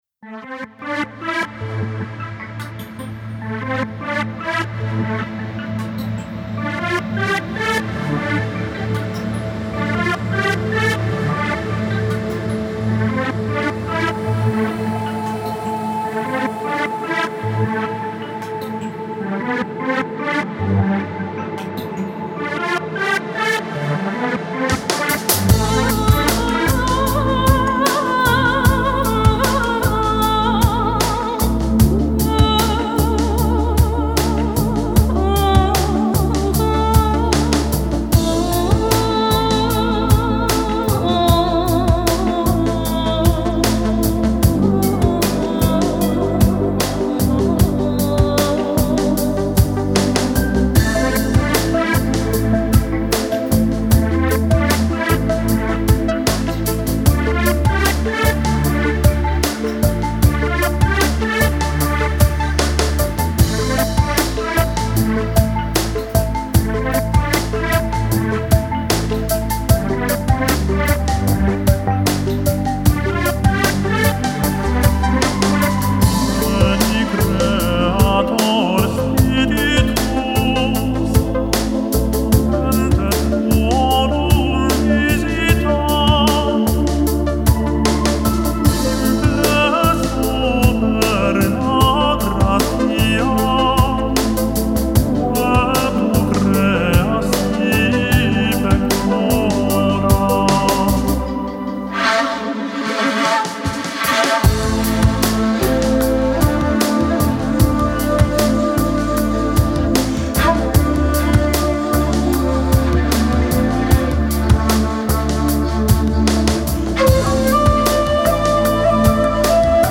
So Finale, habe jetzt etwas die Höhenbeschneidung rückabgewickelt. Man kann es glaube ich ertragen, subtil offener als meine B Version.